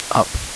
Recovered signal (RLS)
• NLMS appears to be better in first case while RLS is better in second case.